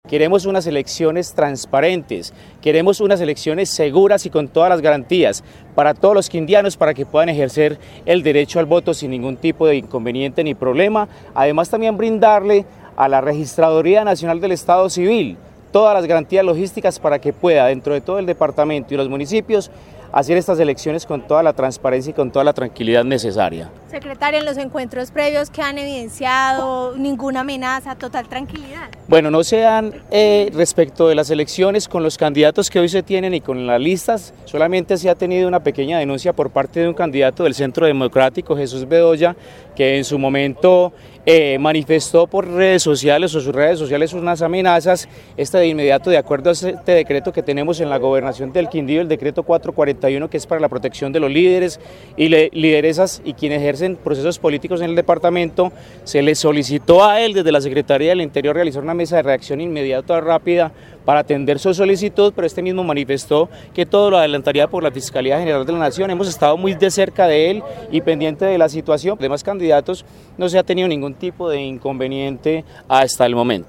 Secretario del Interior del Quindío